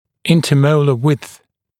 [ˌɪntə’məulə wɪdθ][ˌинтэ’моулэ уидс]расстояние между молярами на противоположных сторонах одной зубной дуги